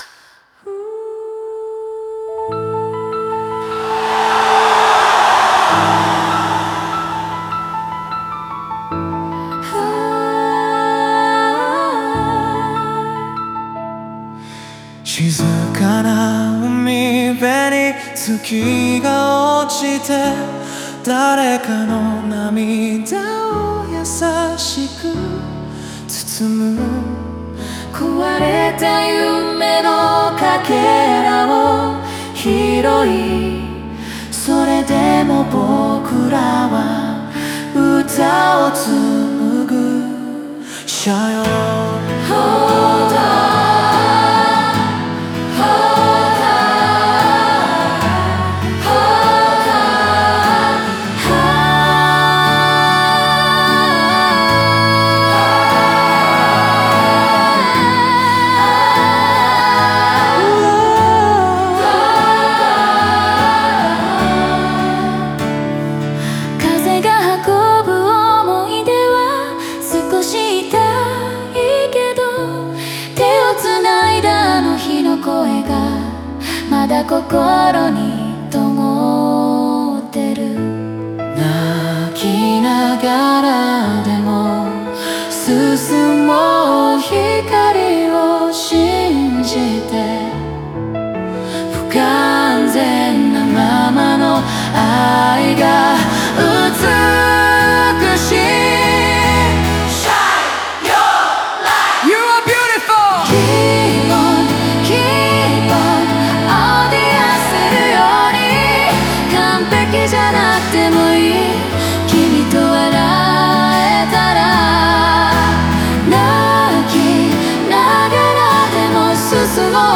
この曲は、人生の不完全さや傷を抱えながらも、希望と愛を信じて前に進む力を描いたフォーク・バラードです。